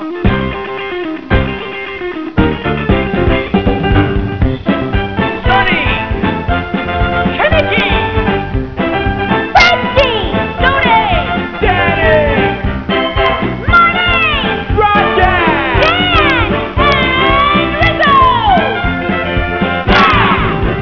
Live Sound Clips from "Grease'